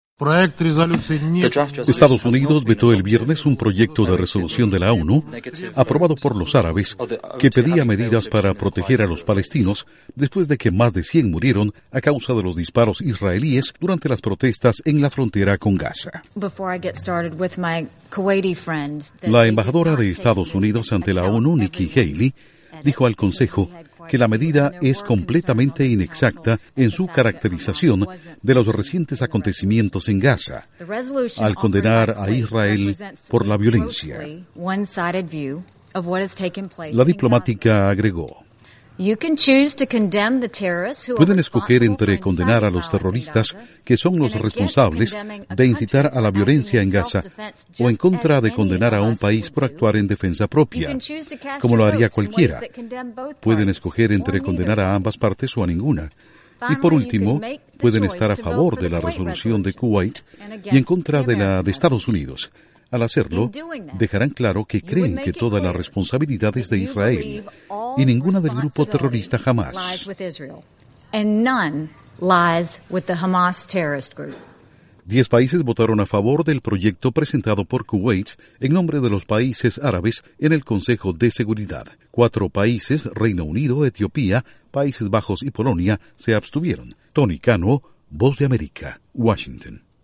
Estados Unidos veta resolución de la ONU para proteger a palestinos. Informa desde la Voz de América en Washington